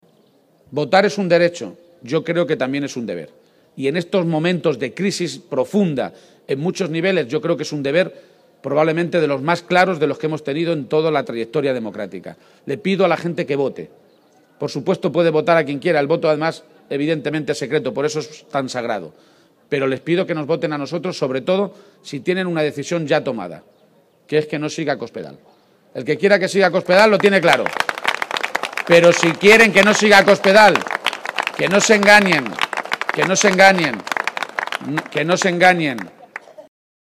En el acto público del PSOE en Caudete, también anunció que si el próximo 31 de Mayo, Día de Castilla-La Mancha, es presidente de la Comunidad Autónoma,- gracias al voto mayoritario de la ciudadanía-, su discurso será “incluyente”, remarcando que la próxima Legislatura representará “la esperanza de una Región que si ha sido capaz de llegar tan lejos en la historia, partiendo de una posición tan atrasada, podremos sobreponernos al bache que ha supuesto Cospedal”.